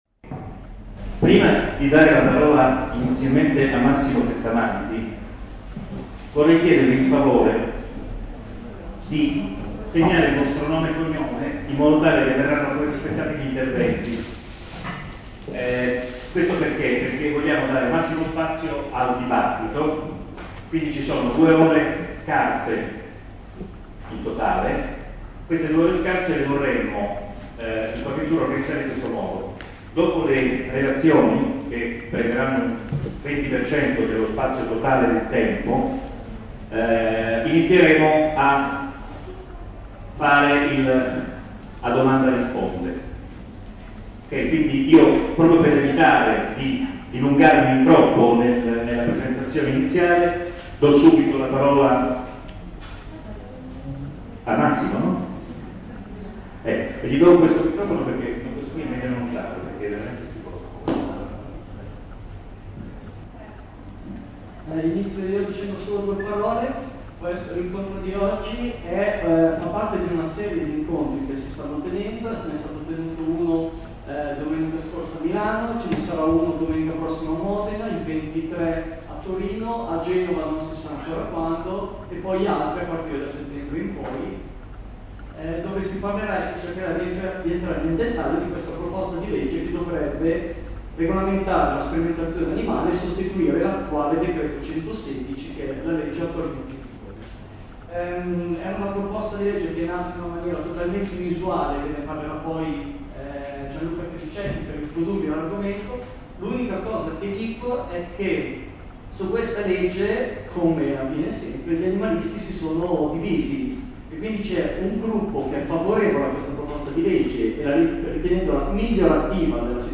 La registrazione AUDIO del dibattito tenutosi alla Facoltà di Giusrisprudenza sulla proposta di legge 2157.
Successissivamente vi sono stati interventi di altri partecipanti.